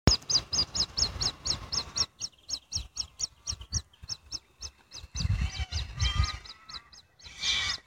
Australasian Grebe
They can often be heard  making a trill type noise at night.
Babies Calling out to their parents for food (This may take a while to load.
Grebe-baby.mp3